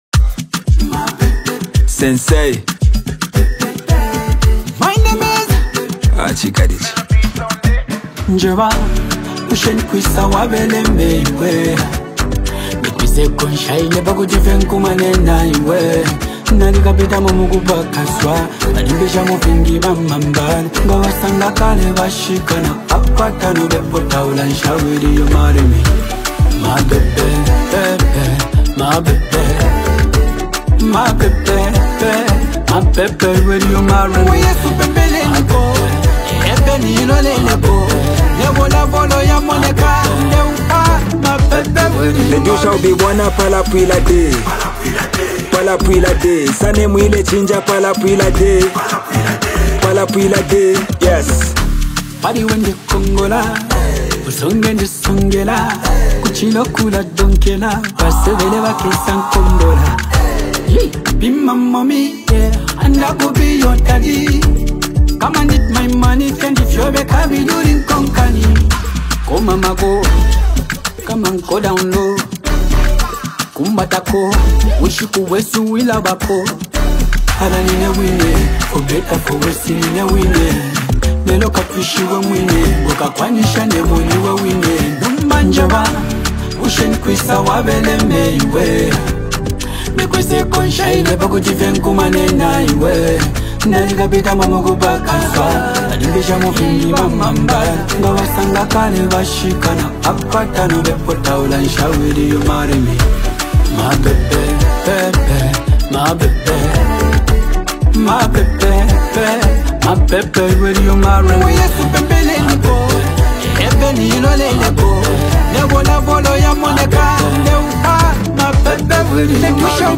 Zambian hip-hop